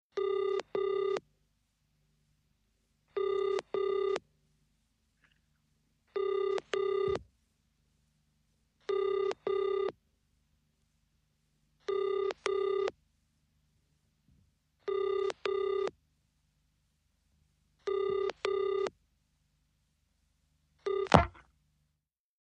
Foreign|Ringing | Sneak On The Lot
British telephone ringing tone, answered on 8th ring ( early 1960`s )